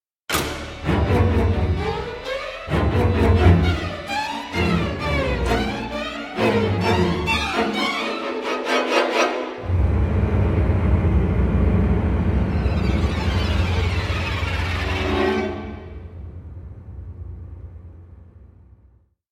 以下试听除弦乐外的其他乐器和打击乐均来自柏林系列，本站均可下载